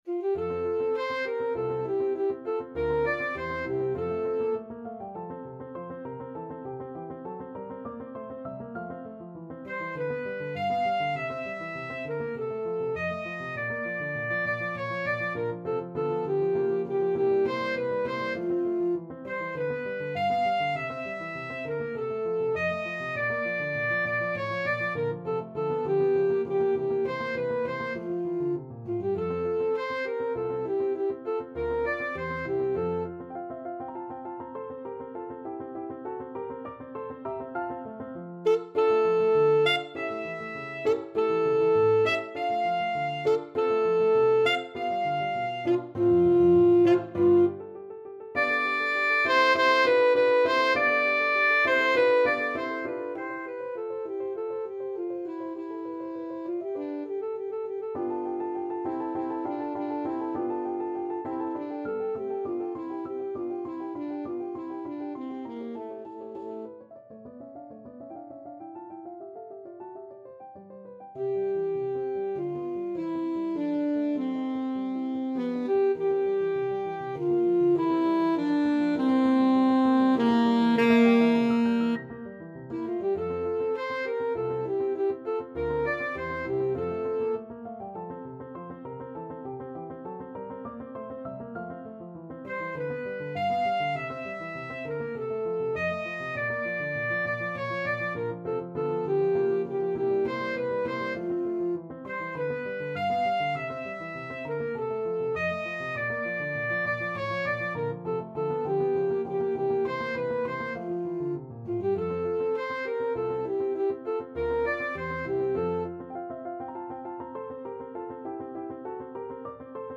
Alto Saxophone version
Alto Saxophone
2/4 (View more 2/4 Music)
G4-G6
Nicht zu geschwind und sehr singbar vorgetragen
Classical (View more Classical Saxophone Music)